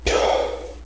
exhale.wav